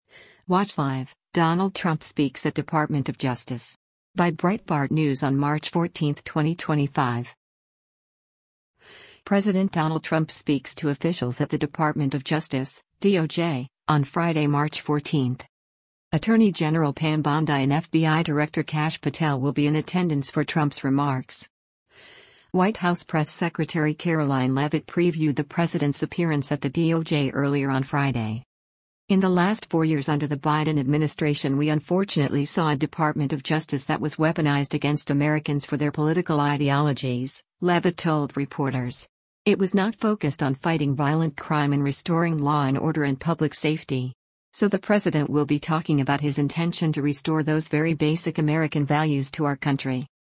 President Donald Trump speaks to officials at the Department of Justice (DOJ) on Friday, March 14.